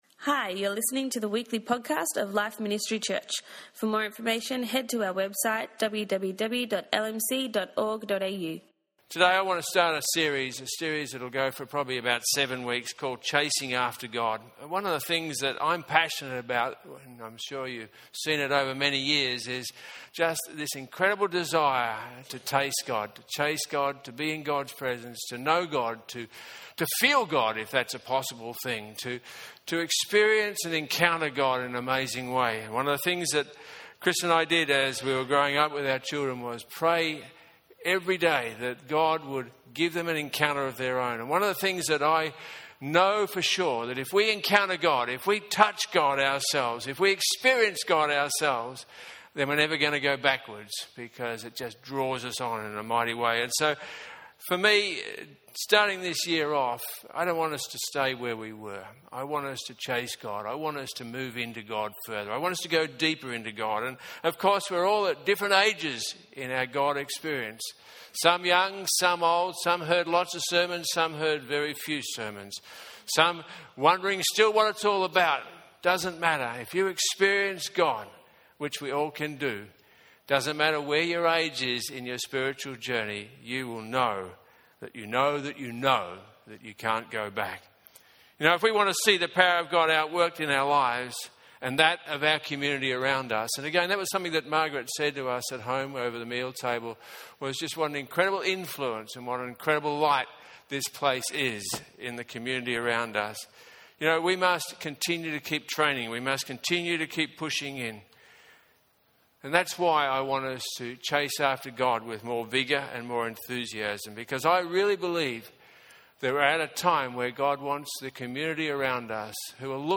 In this first message he looked at the purpose of anointing in our lives.